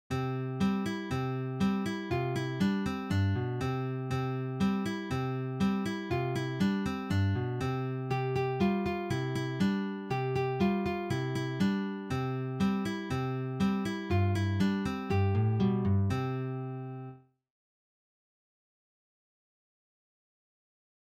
Für Gitarre Solo
Geistliche Musik
Gitarre (1)